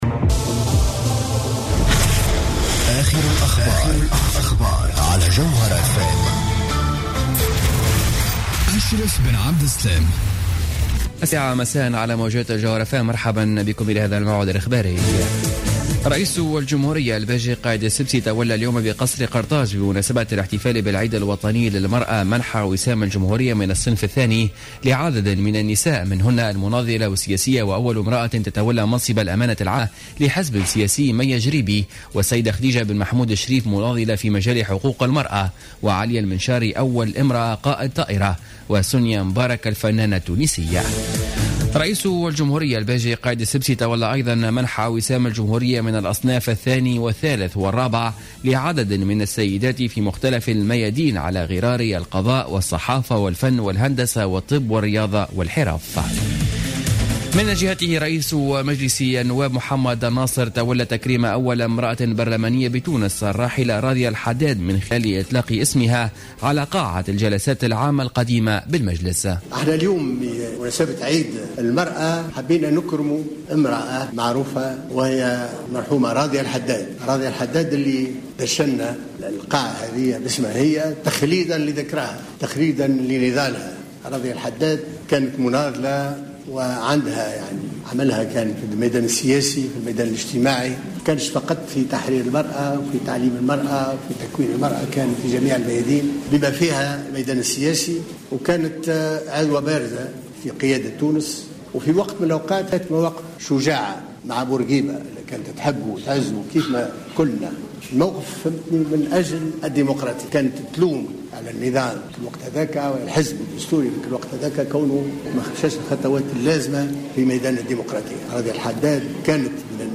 نشرة أخبار السابعة مساء ليوم الخميس 13 أوت 2015